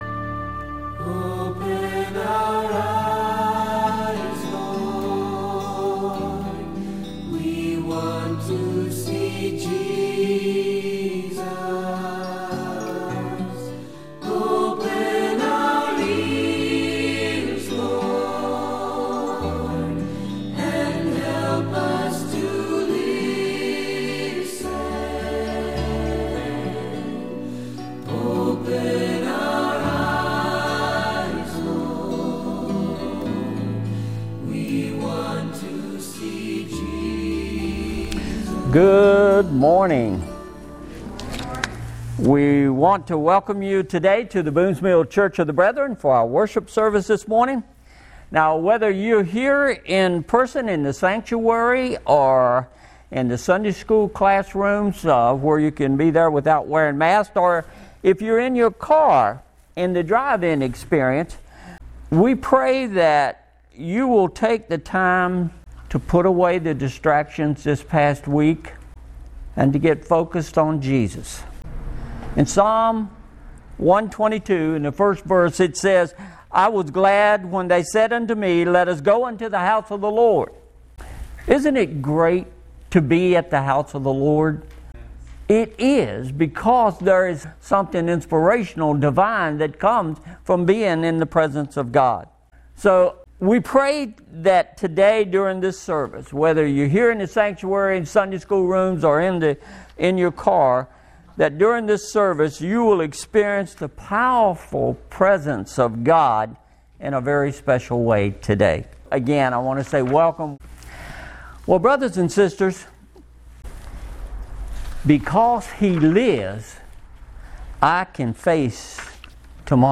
Jan 24, 2021 How to Make Up Your Mind MP3 Notes Discussion Sermons in this Series Sermon Series How to Face Our Future!